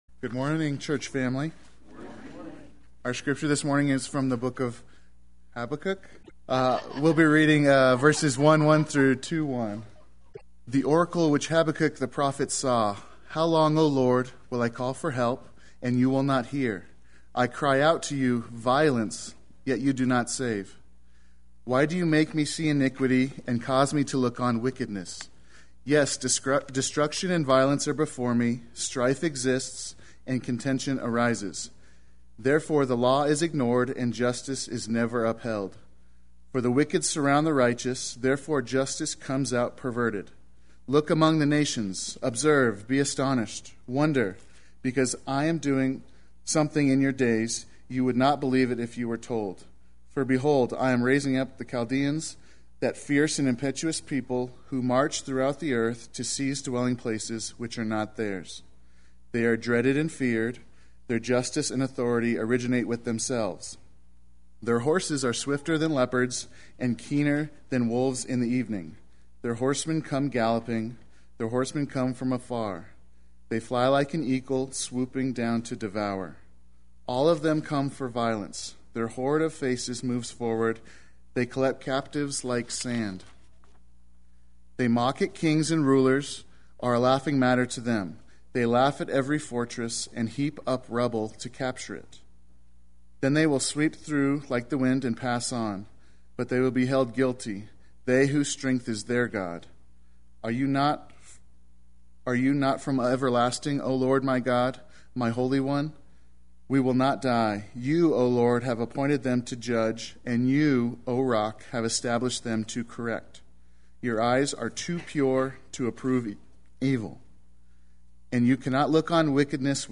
Play Sermon Get HCF Teaching Automatically.
Sunday Worship